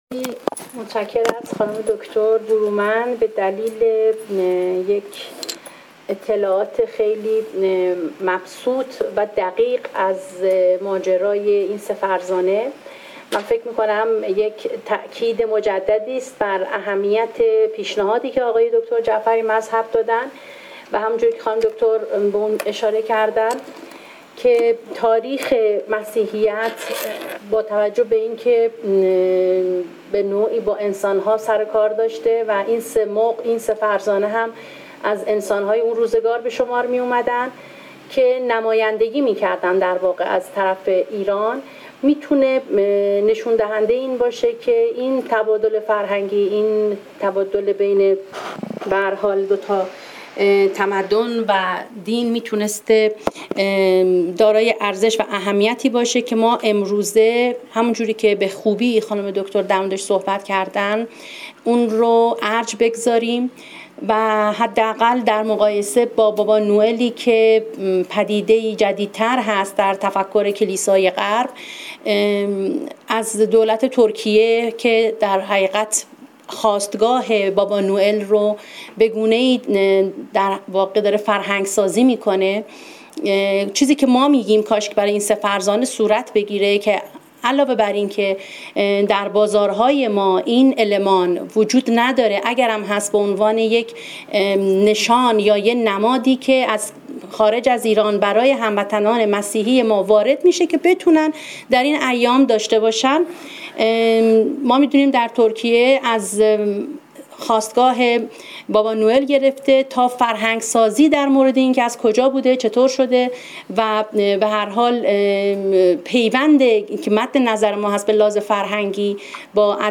پژوهشکده علوم تاریخی برگزار می کند: